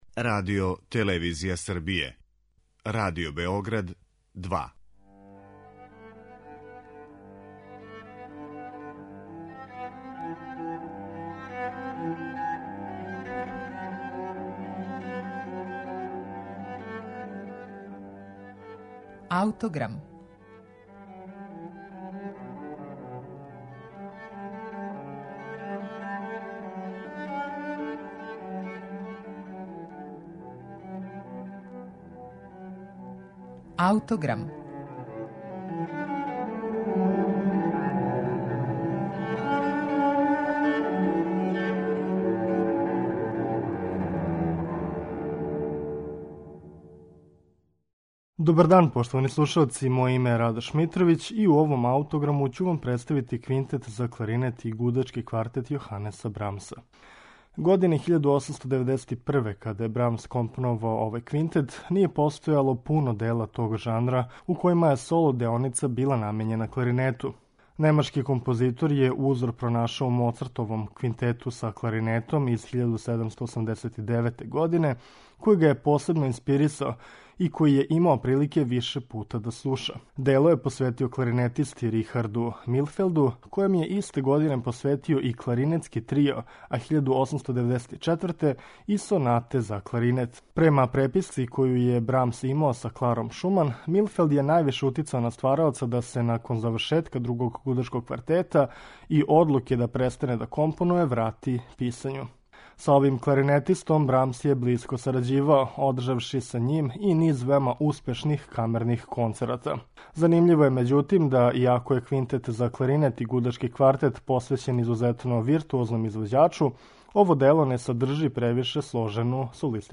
Брамсов квинтет за кларинет и гудачки квартет